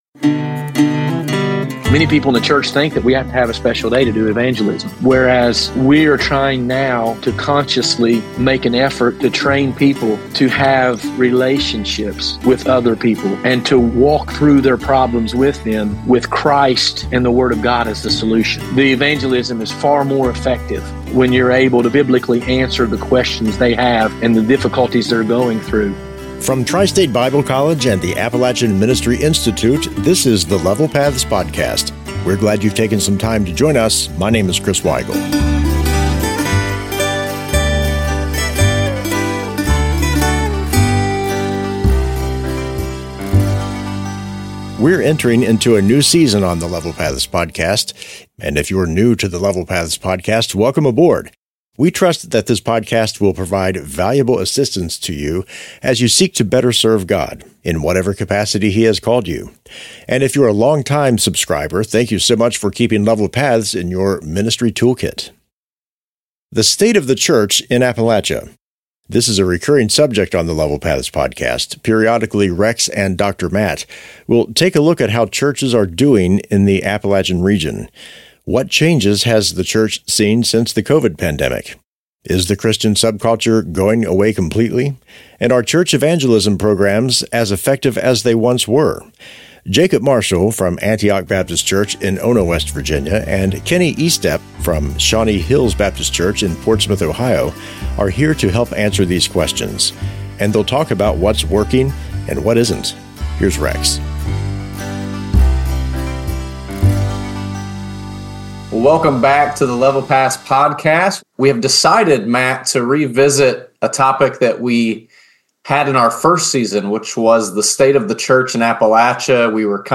Listen and join the conversation about the trends and transformations happening in Appalachian churches today.